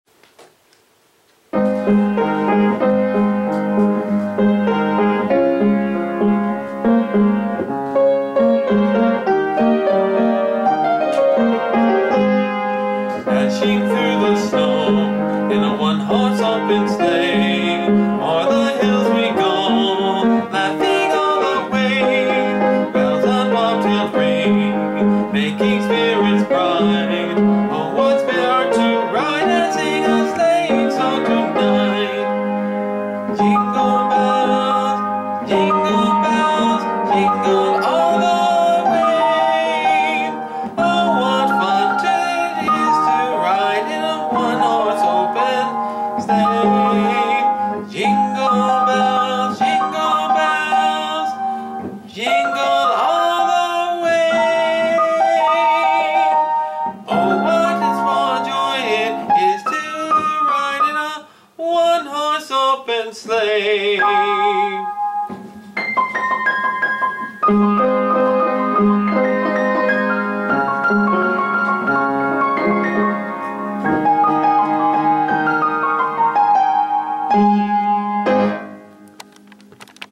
Listen to the Track (note — only one verse, and it is hard to play the chorus accompaniment while singing):